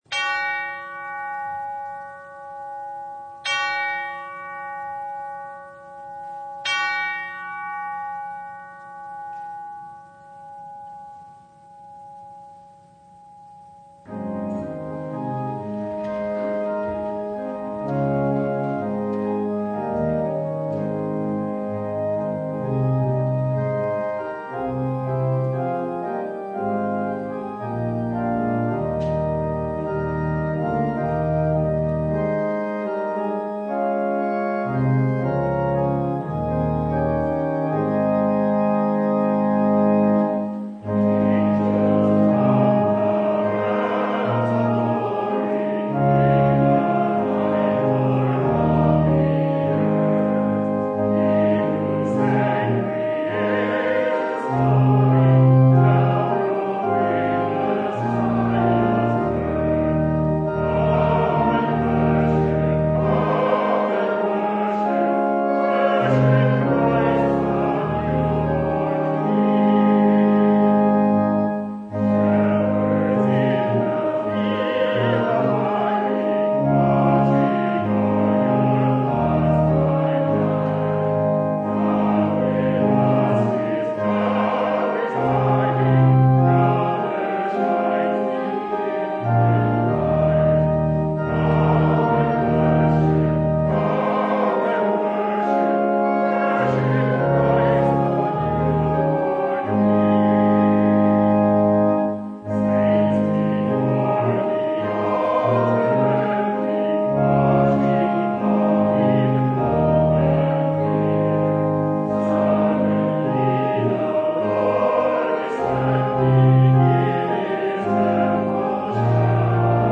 Luke 2:22-40 Service Type: Sunday Was that it?